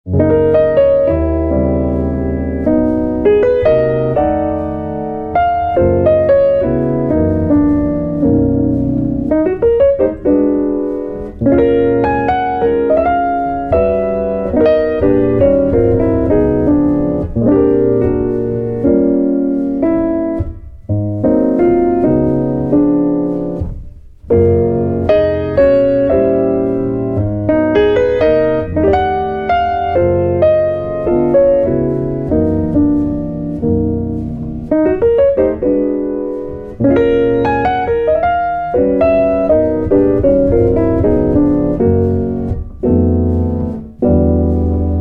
Piano
Guitar